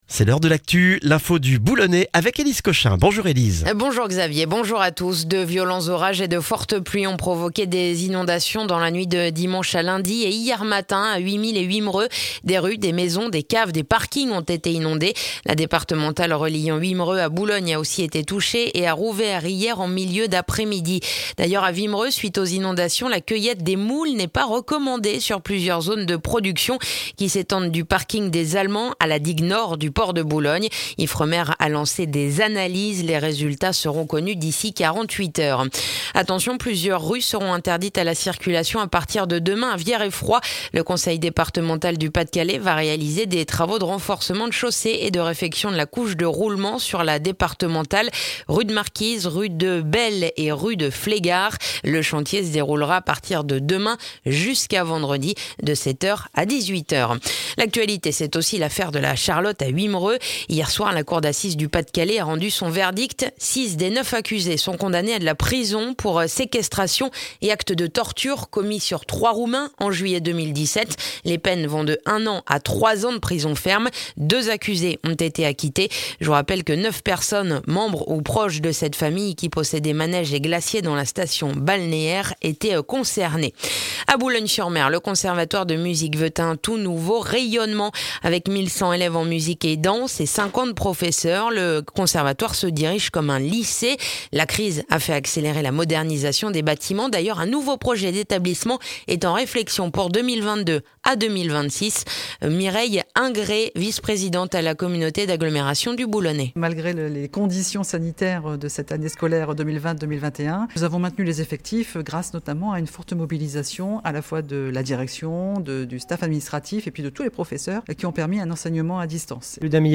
Le journal du mardi 21 septembre dans le boulonnais